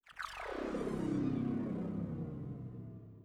respawn.wav